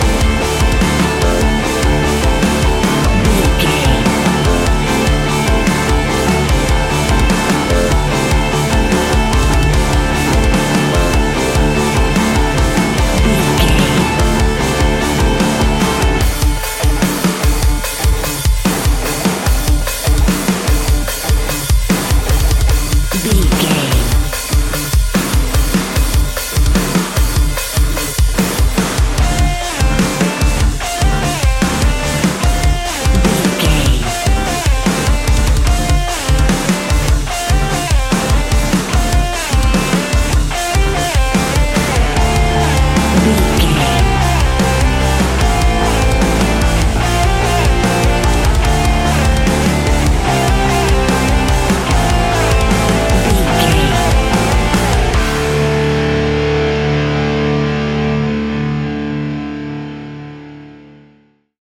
Ionian/Major
D♭
heavy rock
instrumentals